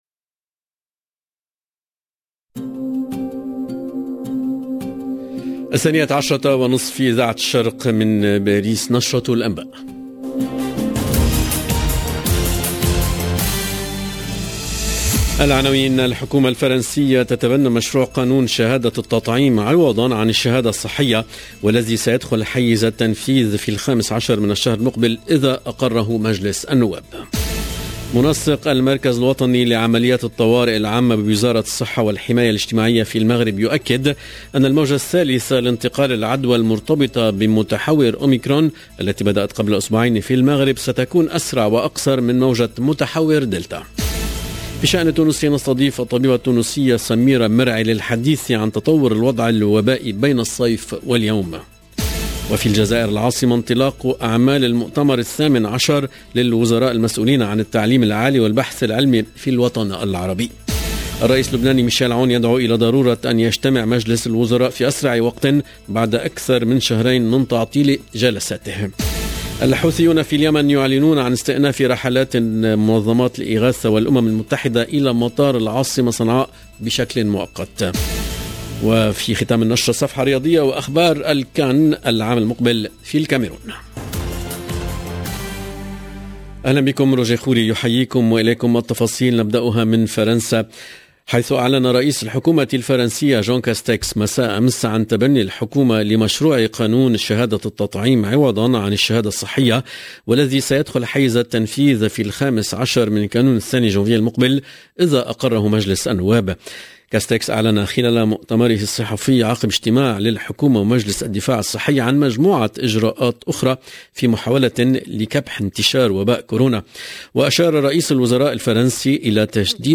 LE JOURNAL DE MIDI 30 EN LANGUE ARABE DU 28/12/21